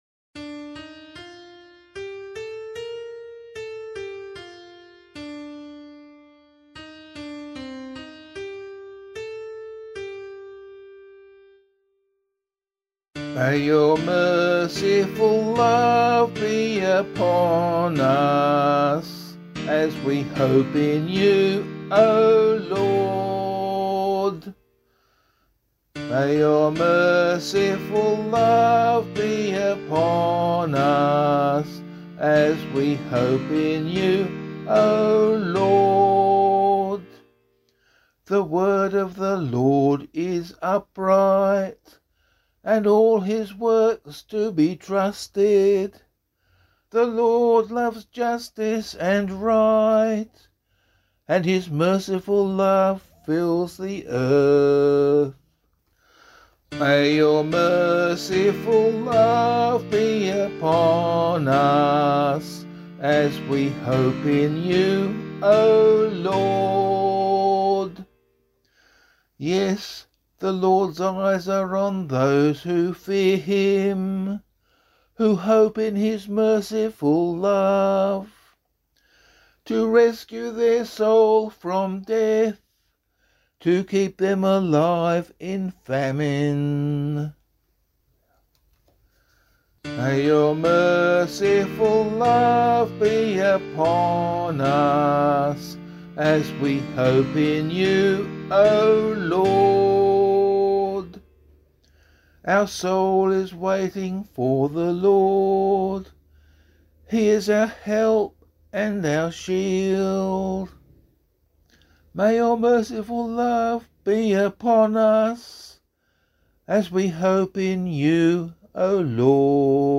014 Lent 2 Psalm A [APC - LiturgyShare + Meinrad 4] - vocal.mp3